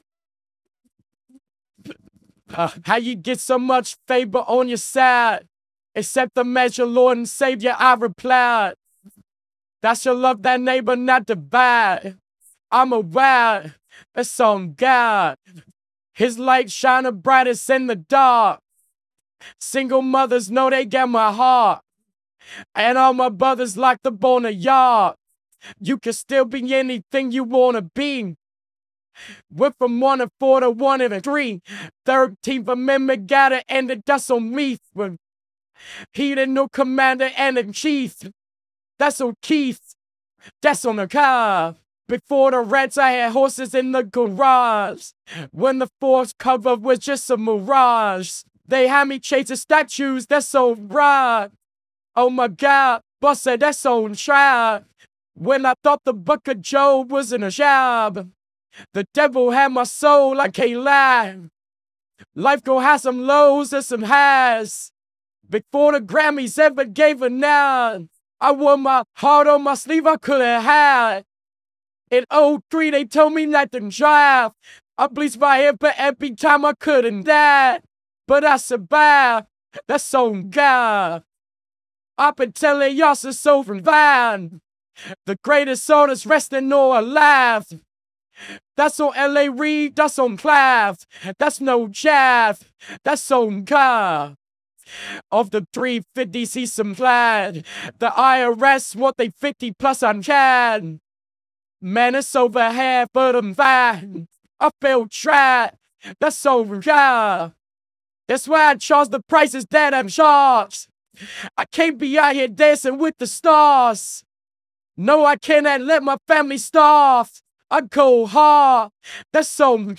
(Retrain)(Tubbo)(Toby Smith)(RVC)(HIFI)(RMVPE)(370 Epochs - 24420 Steps)(32k) AI Voice Model
On_God_Tubbo_output.wav